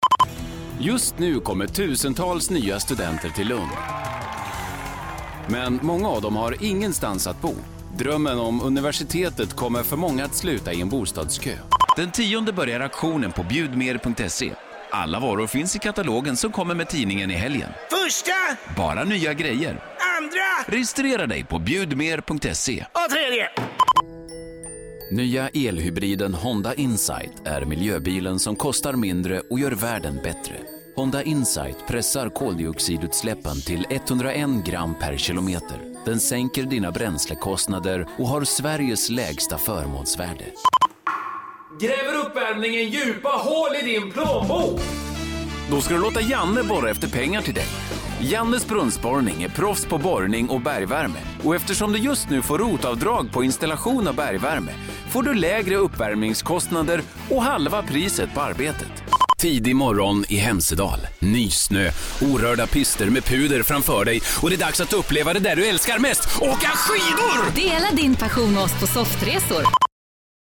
Proffessional voiceoverartist, one of swedens most used voices.
Sprechprobe: Werbung (Muttersprache):